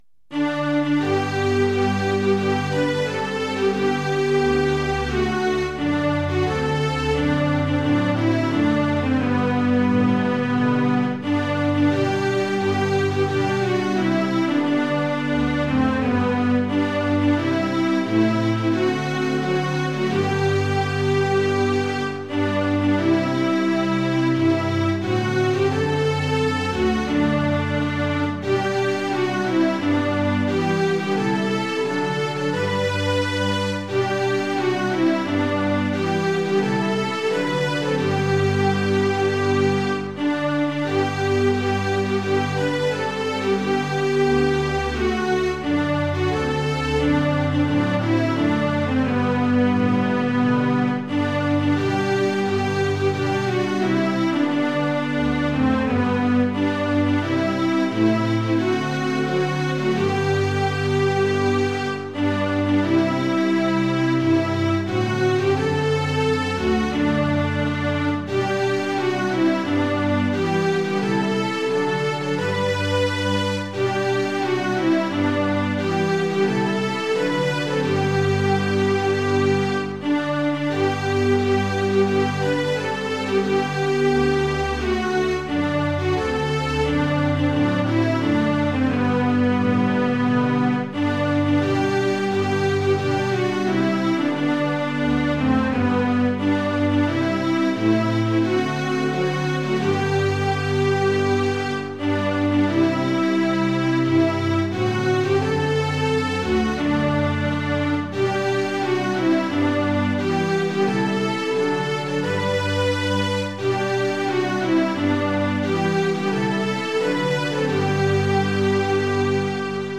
◆　４分の４拍子：　４拍目から始まります。